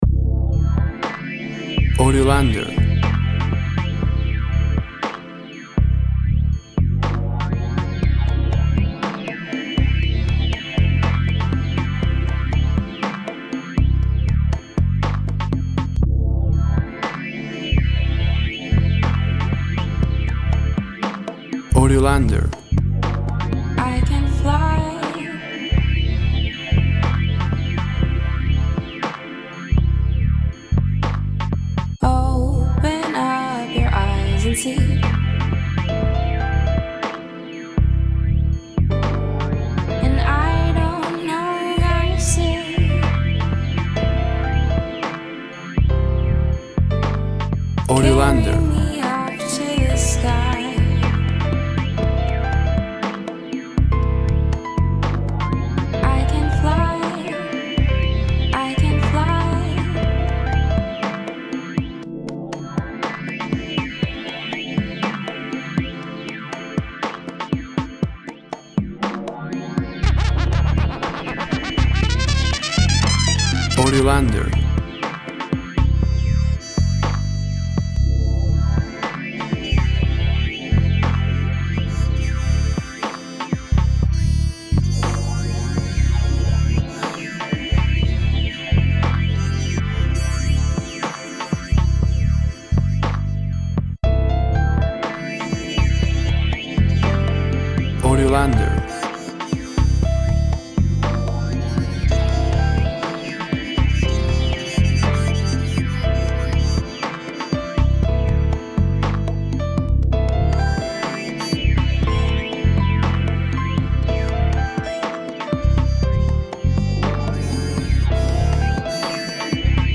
Lounge kind of athmospheric song.